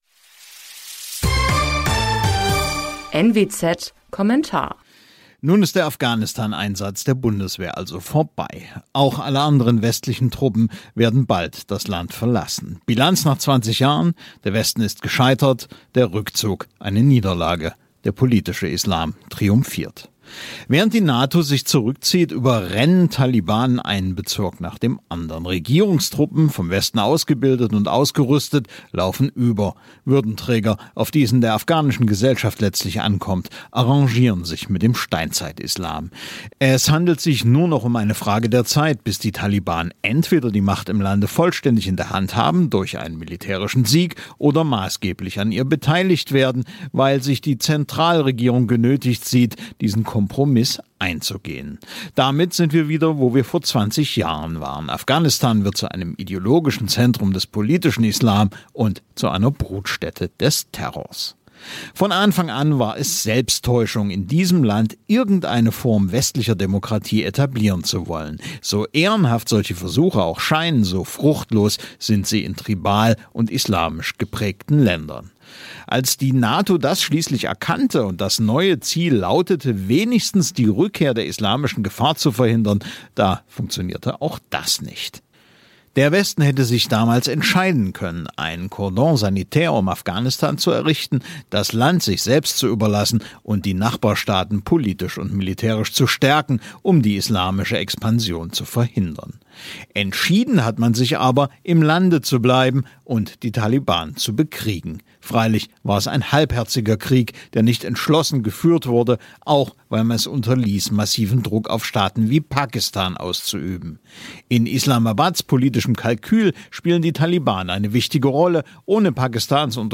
Kommentar zum Rückzug der Bundeswehr